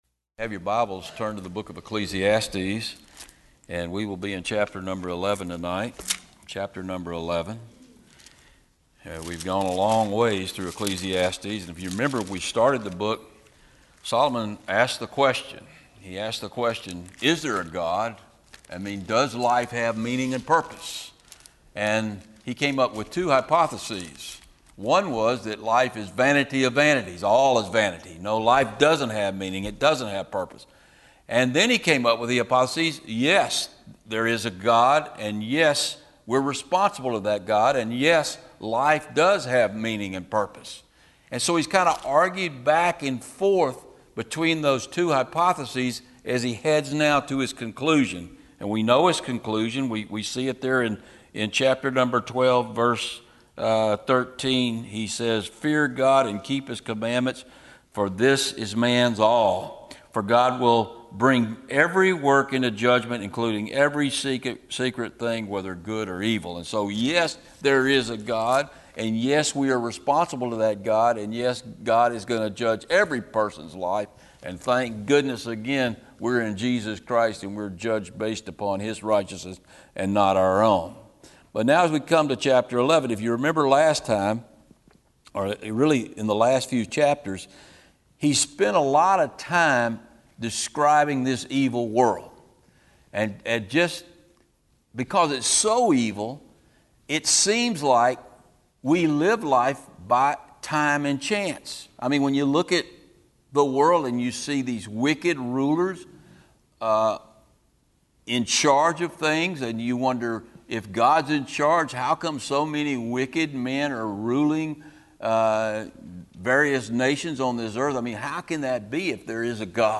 These teachings on Ecclesiastes are from Wednesday evening service.